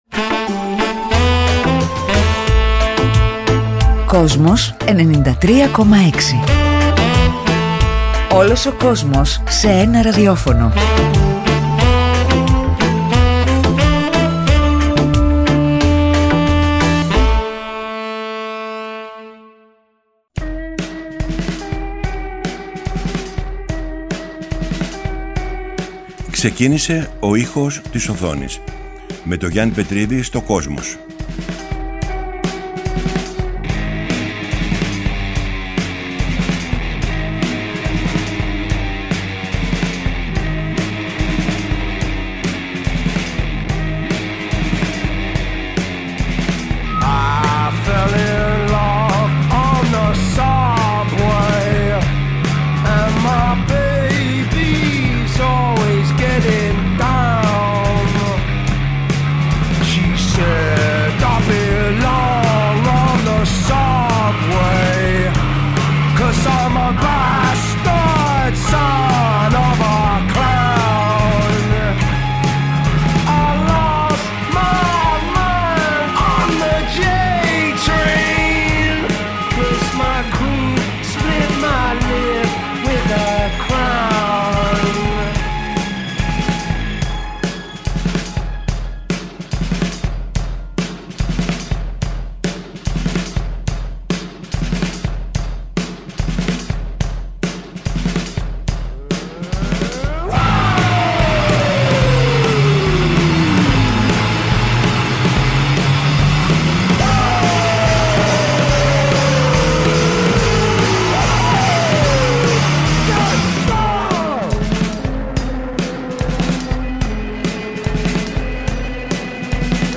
Από την Κυριακή 3 Δεκεμβρίου 2018 ξεκίνησε το αφιέρωμα του Γιάννη Πετρίδη στο Kosmos 93,6, με μουσική και τραγούδια που ξεκίνησαν την καριέρα τους από τον κινηματογράφο και, σε ένα δεύτερο στάδιο, από την τηλεόραση.